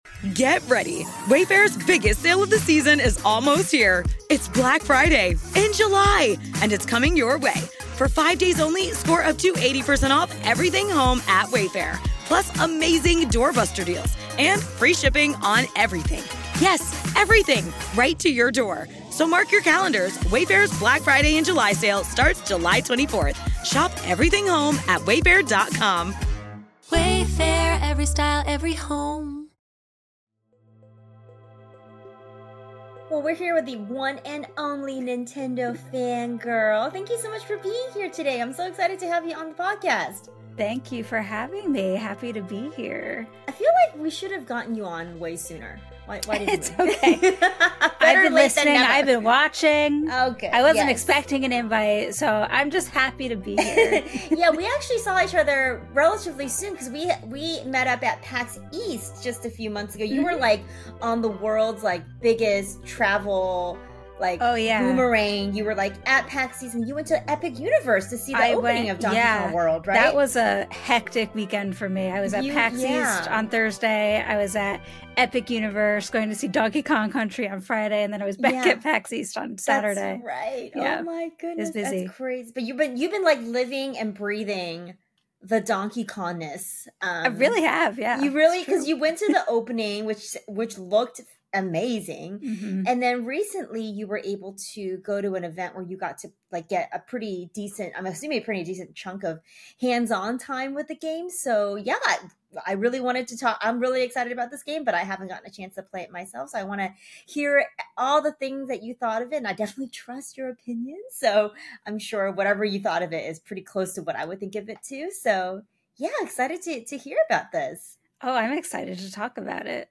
We have a special guest on the podcast today